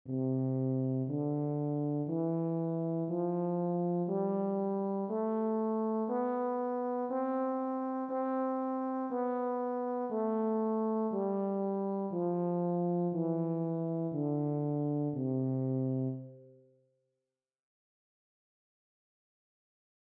Basso Tuba
Tuba.mp3